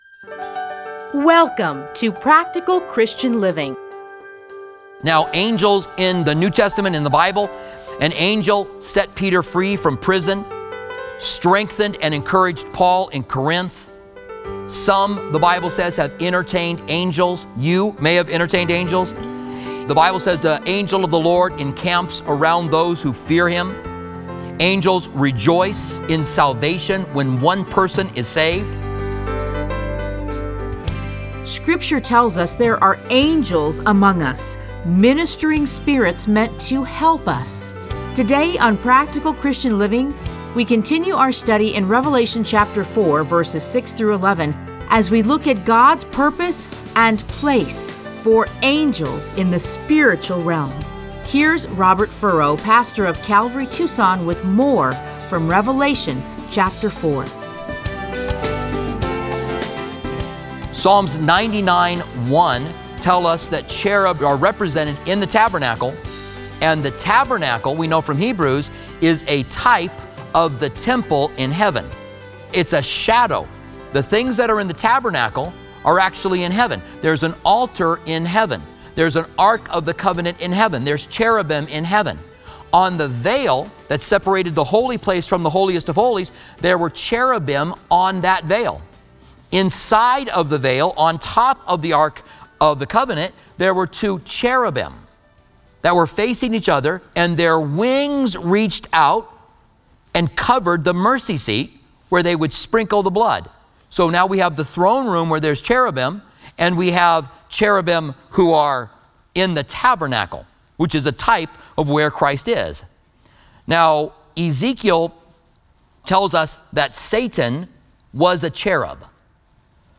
Listen to a teaching from Revelation 4:4-6.